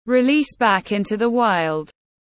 Phóng sinh tiếng anh gọi là release back into the wild, phiên âm tiếng anh đọc là /rɪˈliːs bæk ˈɪn.tuː ðiː waɪld/
Release back into the wild /rɪˈliːs bæk ˈɪn.tuː ðiː waɪld/